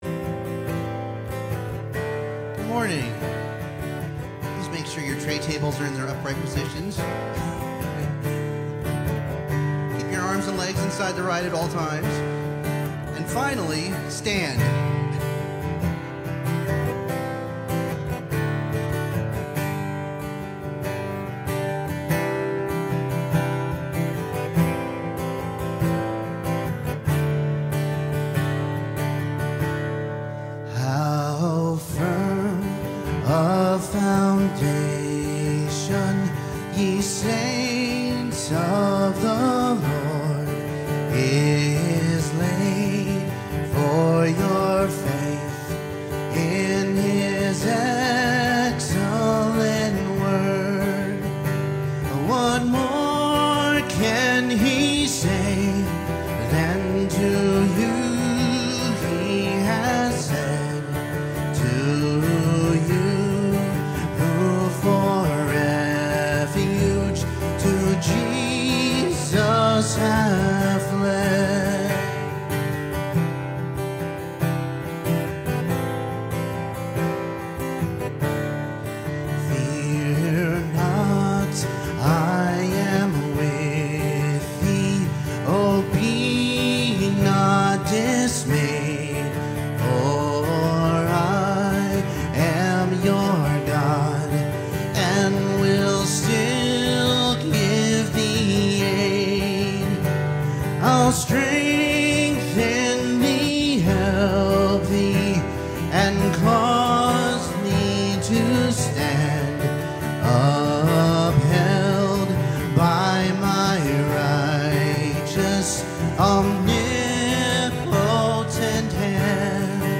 Passage: Ephesians 1:11-14 Service Type: Sunday Morning